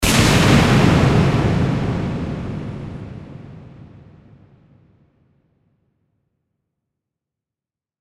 BOOM_ECHO.ogg